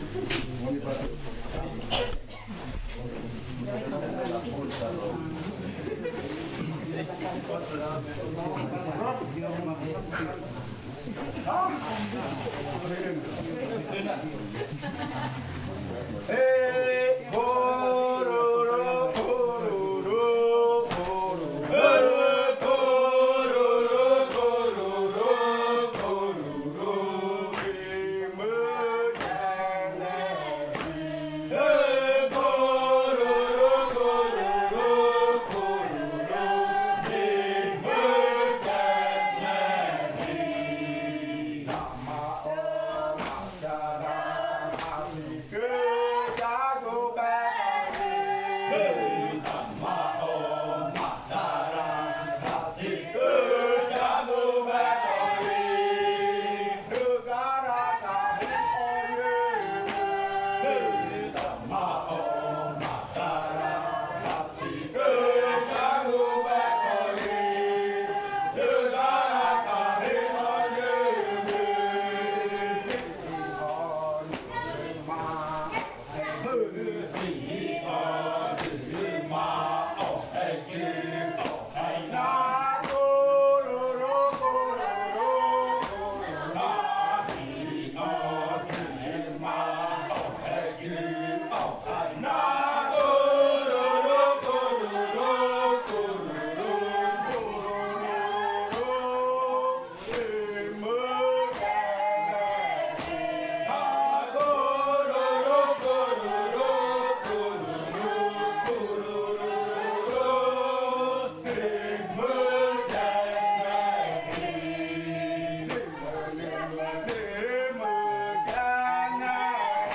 Canto de saltar de la variante jimokɨ
Leticia, Amazonas
con el grupo de cantores bailando en Nokaido.